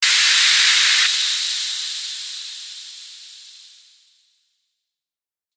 次级噪声器低音延时播放130bpm
描述：一个带着一些延迟器和结巴效果的次要房子低音 130 A
Tag: 130 bpm House Loops Bass Loops 636.23 KB wav Key : A